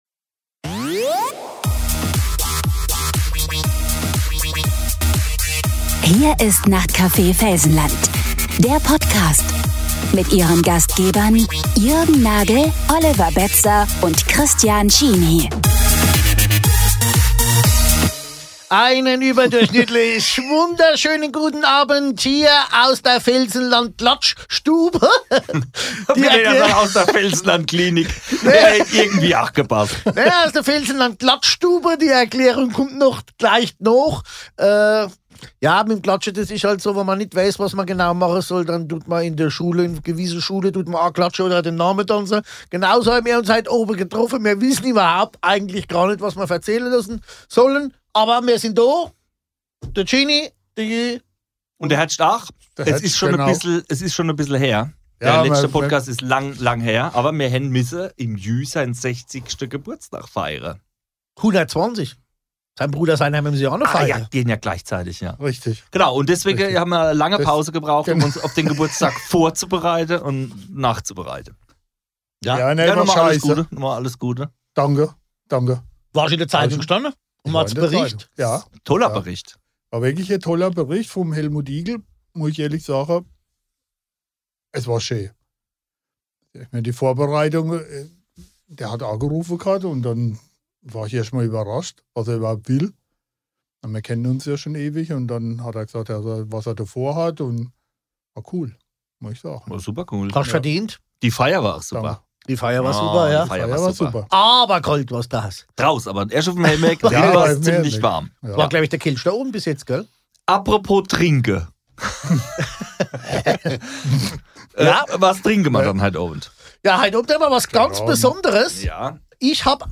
Genres: Comedy, Comedy Interviews